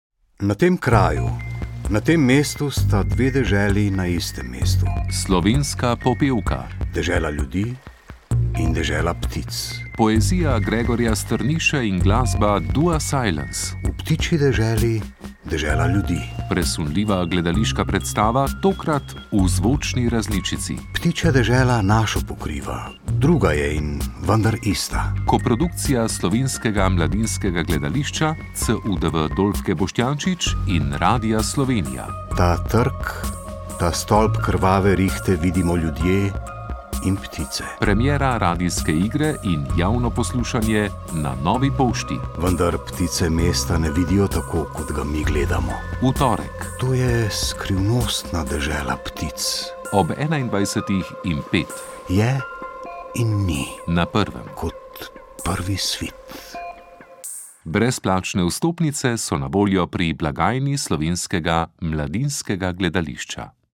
Javno poslušanje radijske igre Slovenska popevka
RI_SLOVENSKA_POPEVKA_promo.mp3